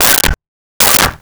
Dog Barking 09
Dog Barking 09.wav